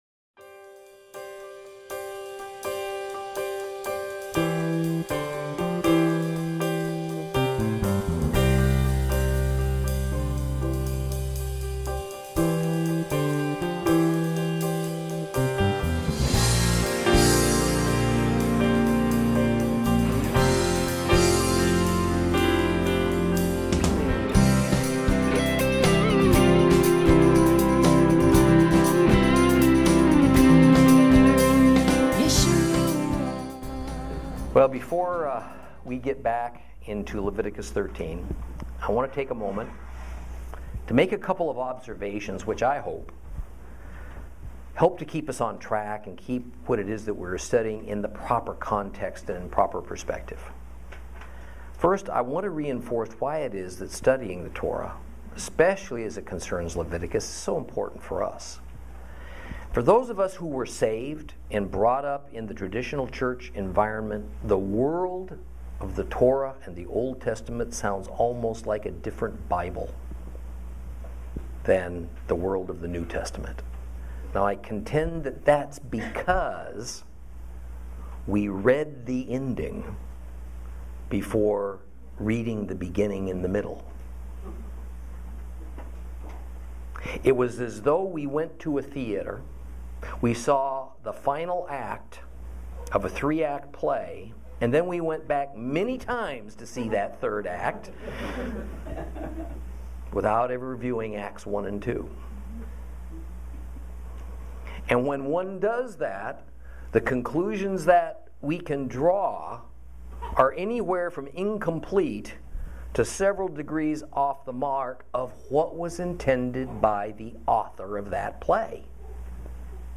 Lesson 20 Ch13 - Torah Class